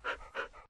panting.ogg